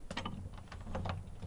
rudder-trim.wav